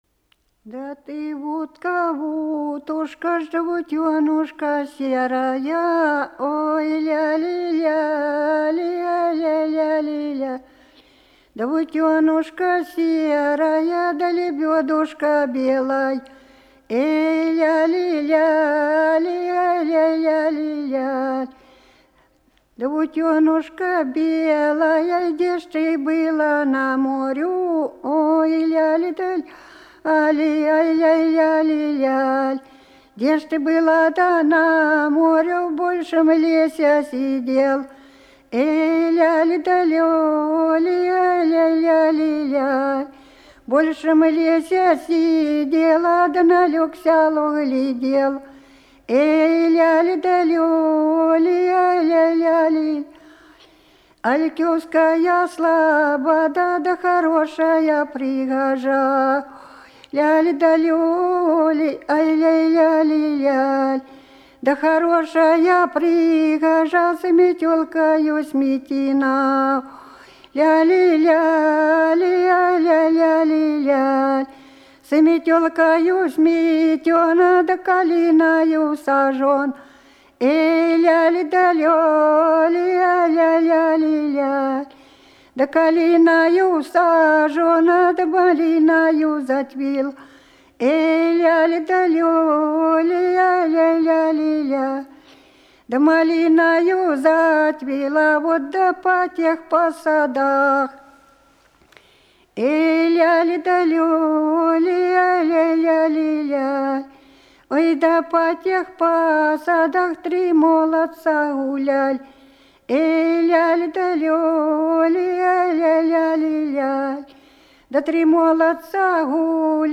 Голоса уходящего века (Курское село Илёк) Да ты утка-утушка (таночная, на Пасху